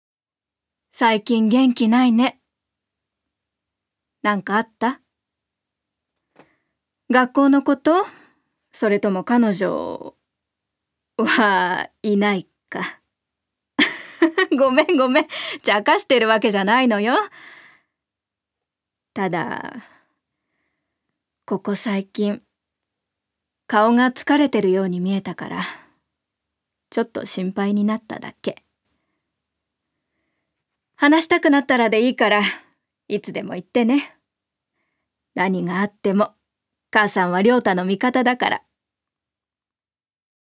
女性タレント
セリフ５